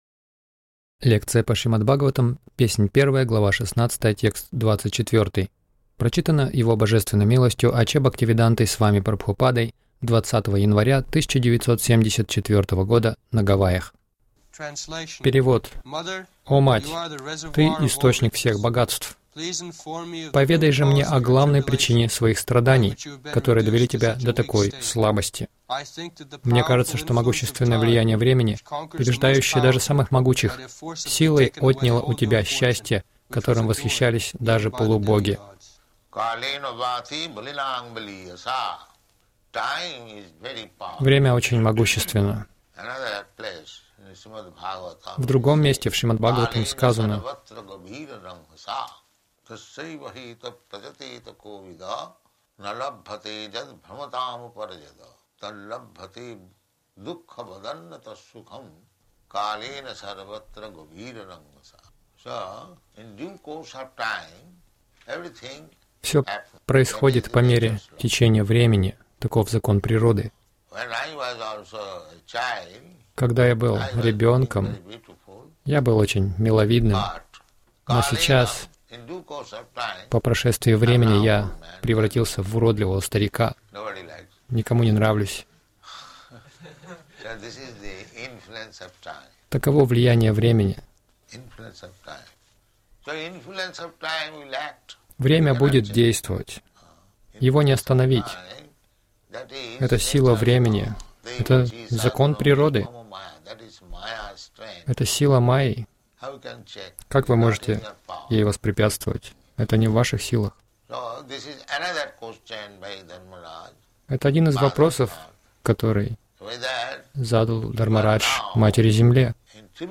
Милость Прабхупады Аудиолекции и книги 20.01.1974 Шримад Бхагаватам | Гавайи ШБ 01.16.24 — Сам Кришна и Его проявления Загрузка...